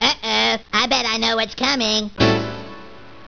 sp_chord.wav